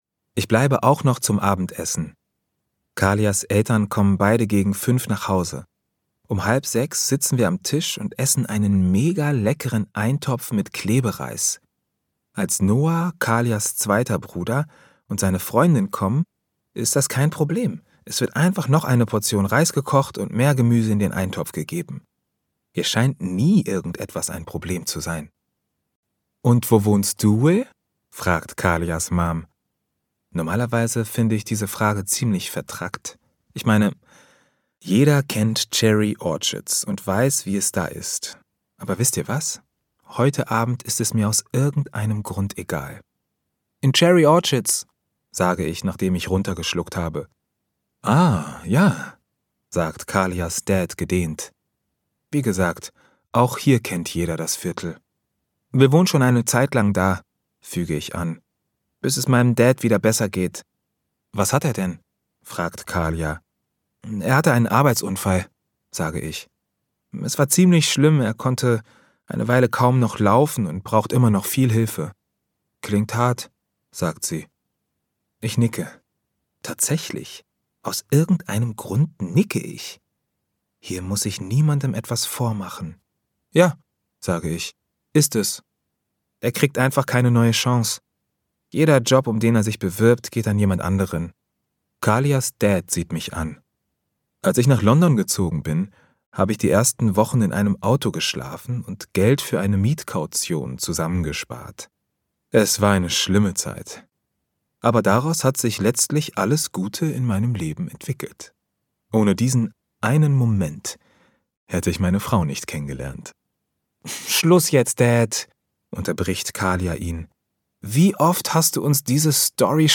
Kinderhörbuch über Armut für Kinder ab 8 Jahren
2025 | 1. Auflage, Ungekürzte Ausgabe